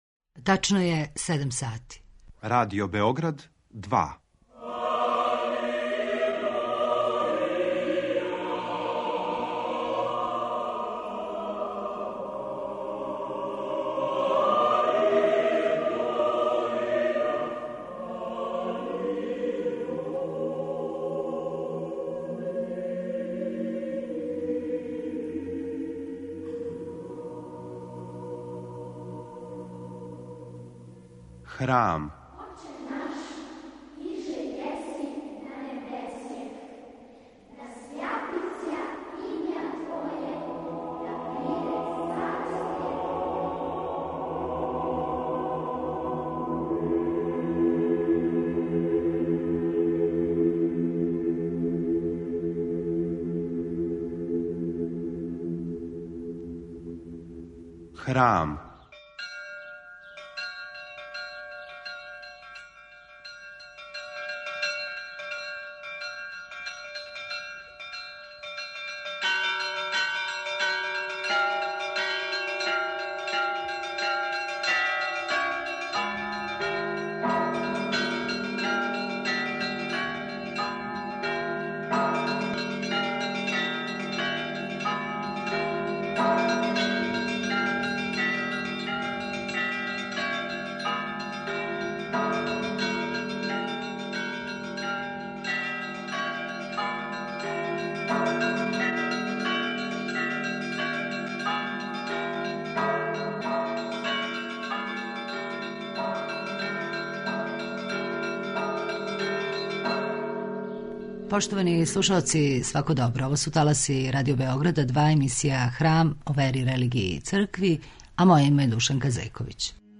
Емисија о вери, религији, цркви...